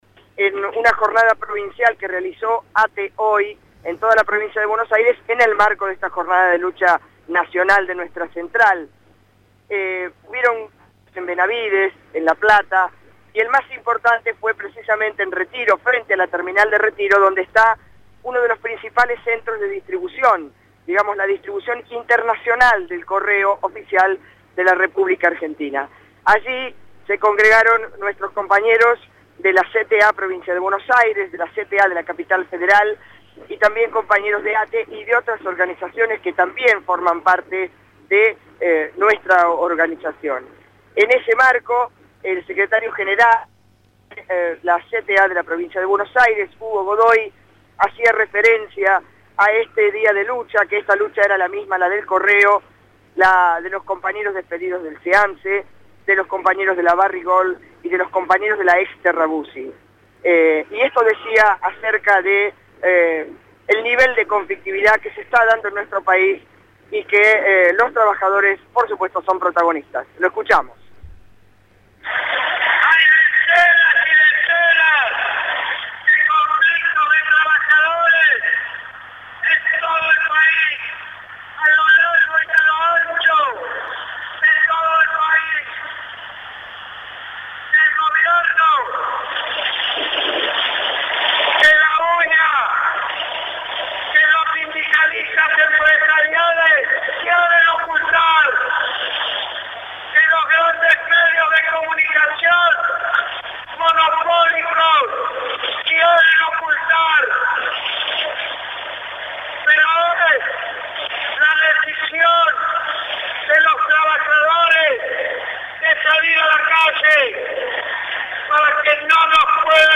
Movil desde el acto en el correo para Radio Central
acto_en_el_Correo.mp3